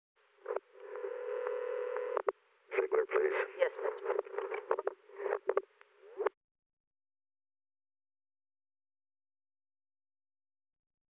Secret White House Tapes
Location: White House Telephone
The President talked with the White House operator.
Telephone call to Ronald L. Ziegler